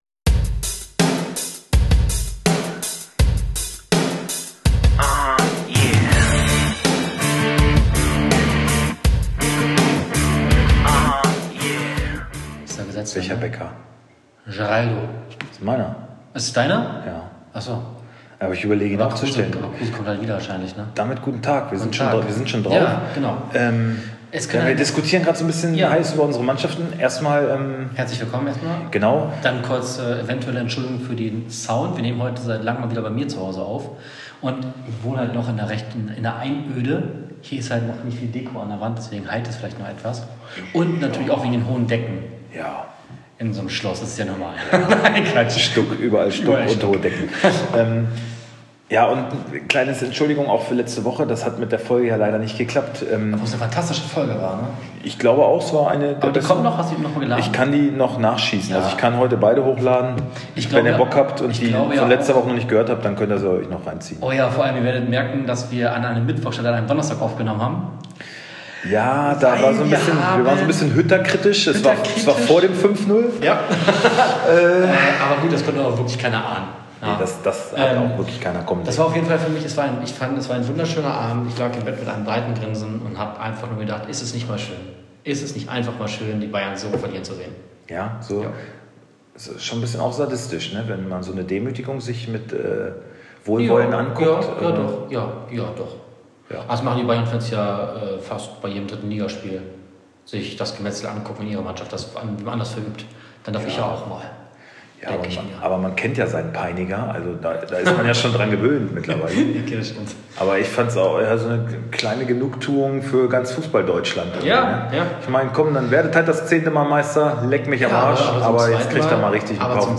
Ausserdem blicken wir auch kritisch und analytisch auf den vergangenen Spieltag und kommentieren volksnah, mit Esprit, Härte, Witz und der nötigen Ironie.